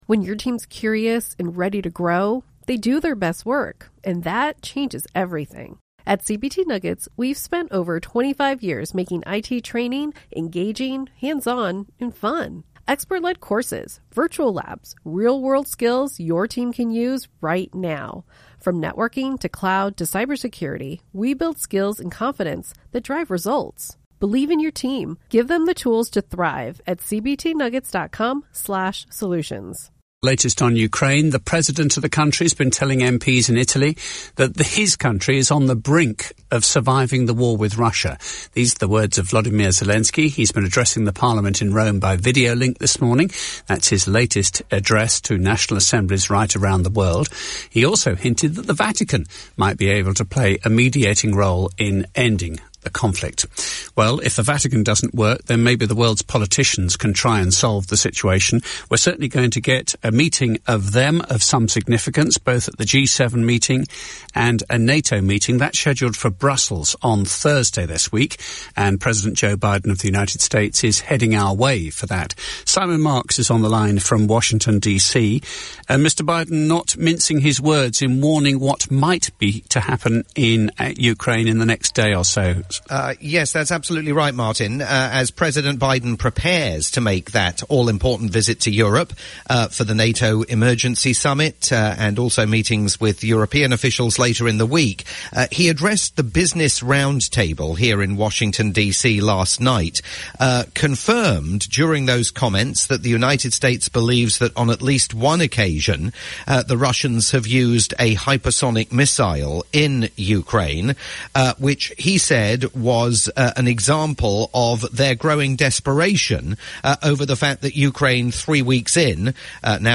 live update for LBC News